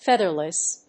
/ˈfɛðɝlʌs(米国英語), ˈfeðɜ:lʌs(英国英語)/